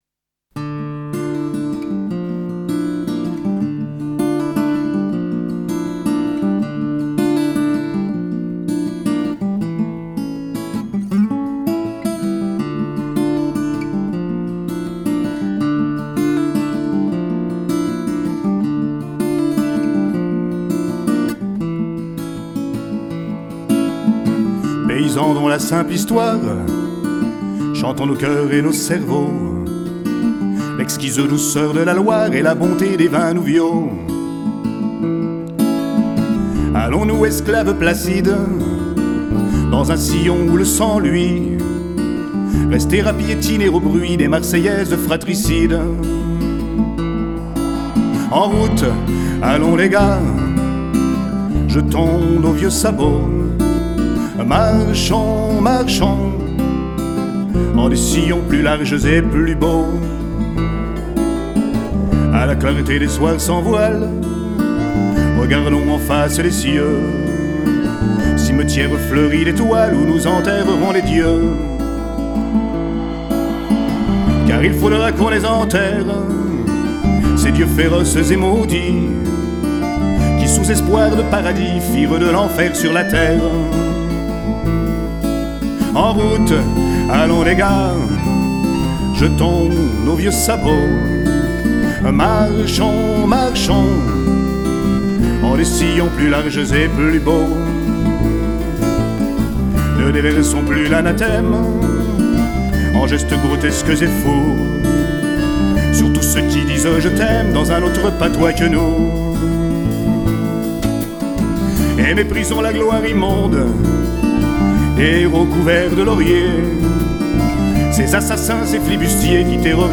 voix guitare